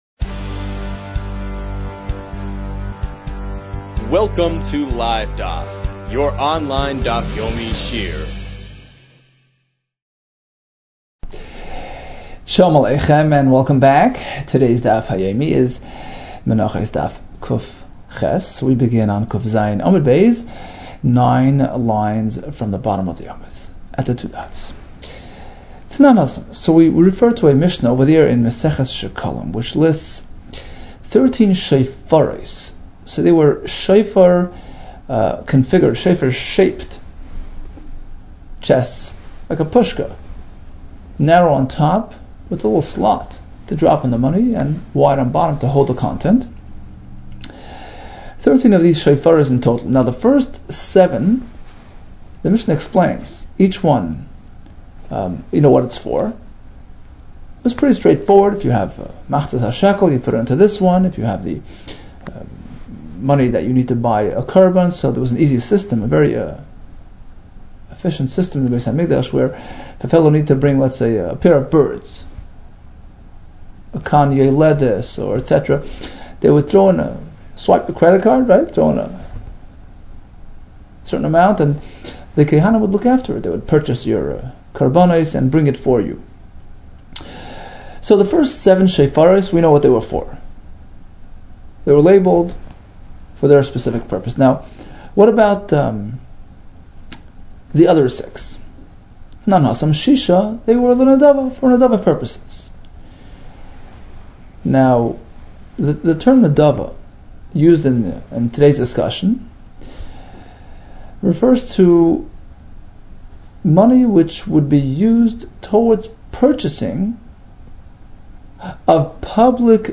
Menachos 108 - מנחות קח | Daf Yomi Online Shiur | Livedaf